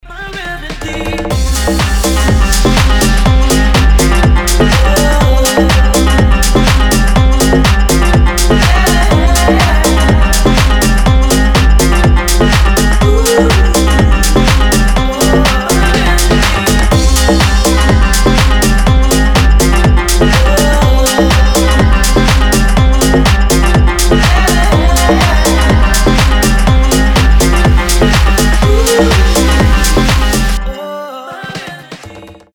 • Качество: 320, Stereo
громкие
мелодичные
восточные
Немного востока в этом ритмичном рингтоне